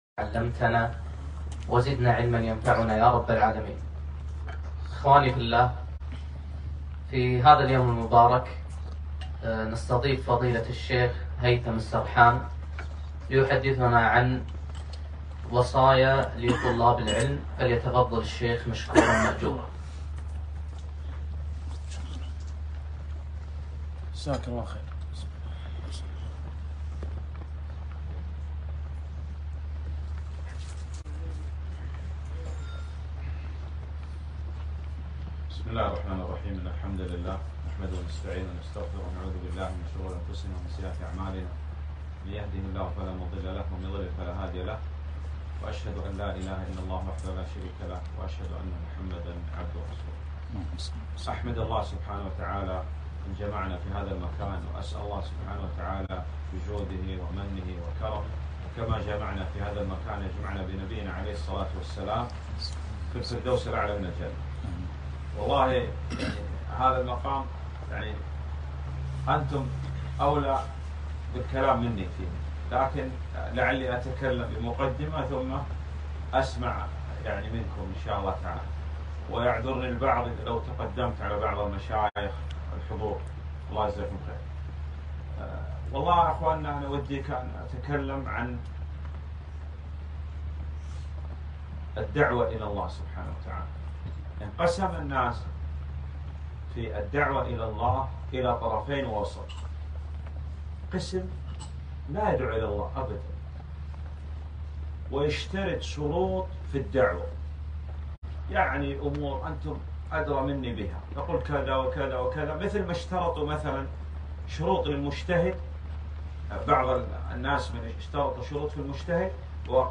محاضرة - ( وصايا لطلاب العلم )